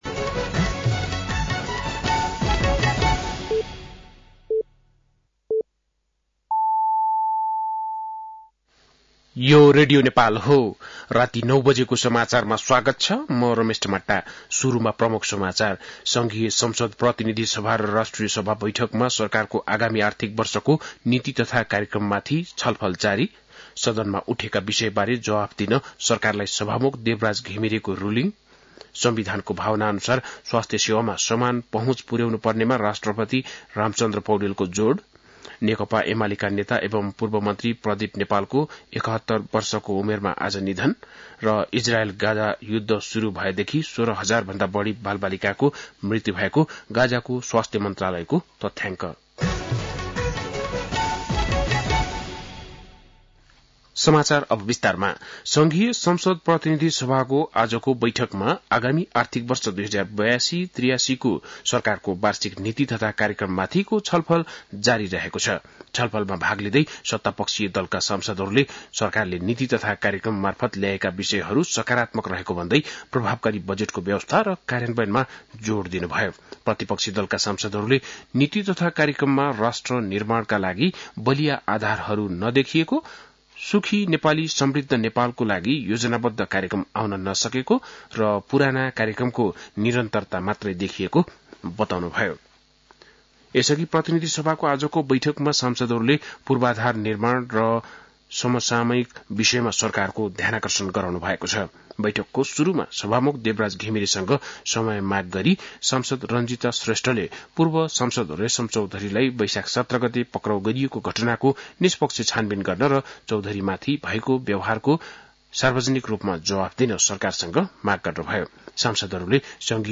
बेलुकी ९ बजेको नेपाली समाचार : २४ वैशाख , २०८२